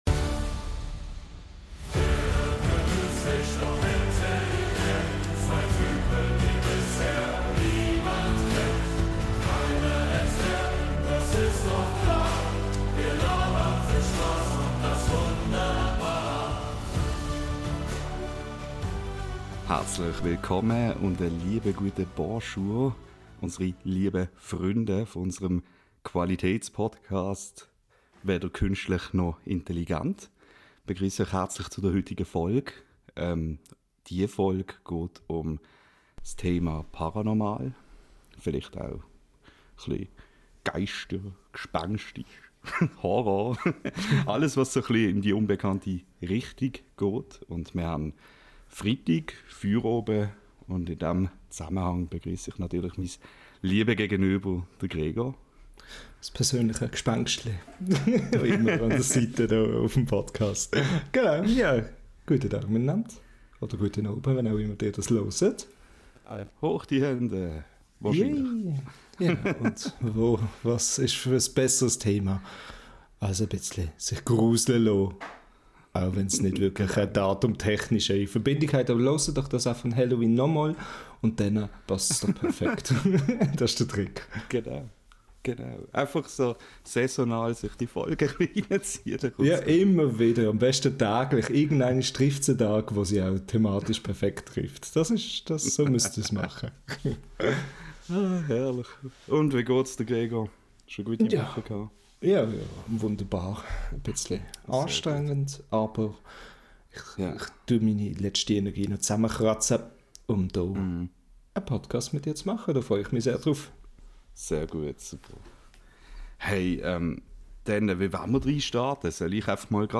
Beschreibung vor 1 Jahr In der zweiten Folge unseres Schweizerdeutschen Podcasts erwarten euch nicht nur gruselige Momente, sondern auch jede Menge Lacher. Wir nehmen euch mit auf eine Reise durch ernste, leichte und völlig absurde Themen.